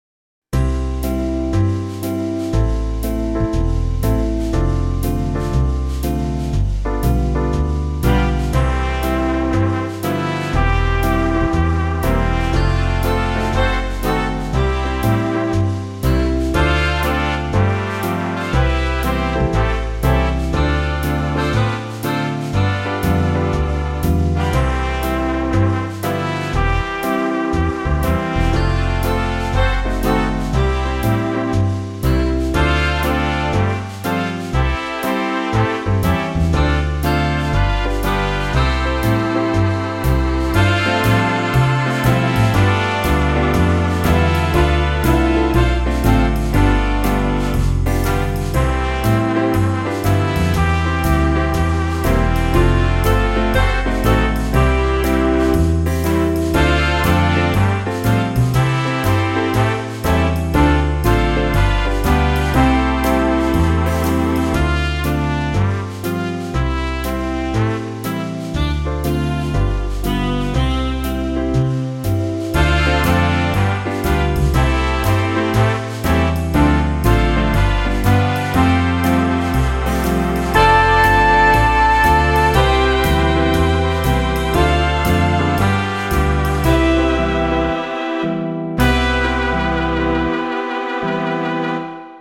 Swing Orchester 1940